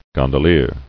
[gon·do·lier]